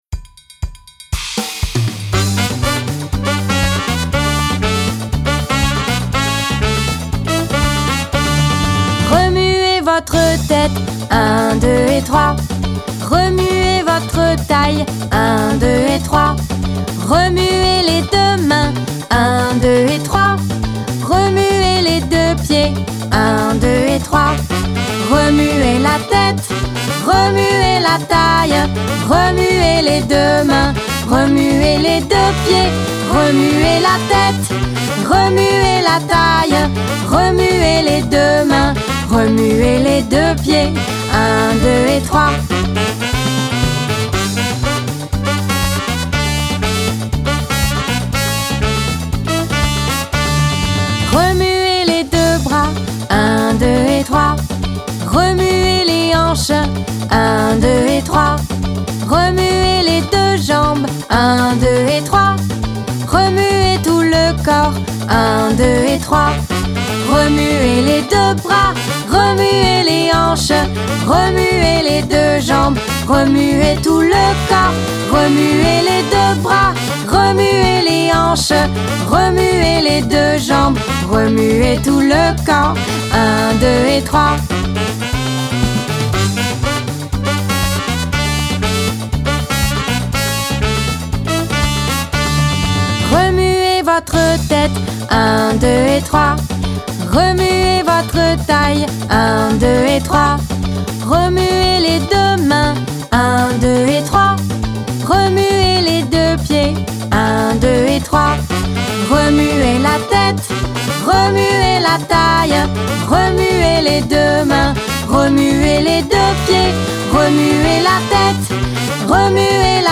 Cette chanson énergique et participative